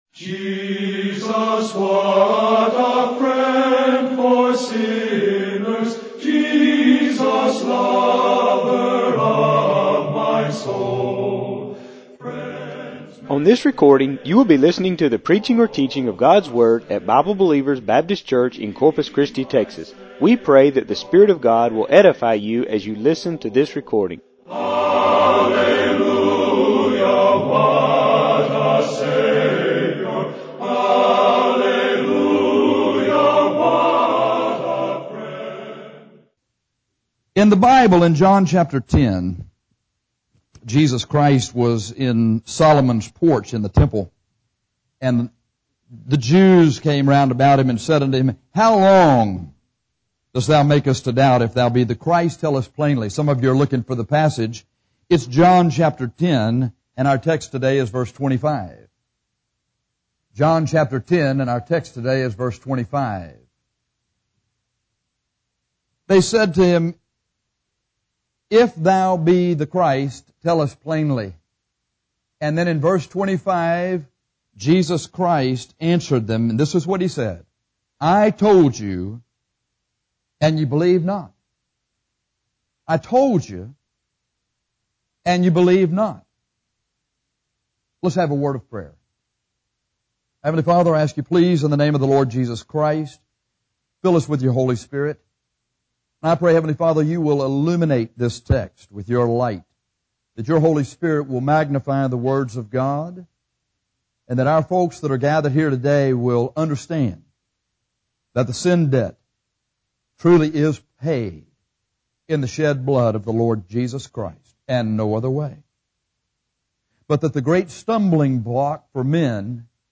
This sermon examines why men fail to believe that Jesus will save them.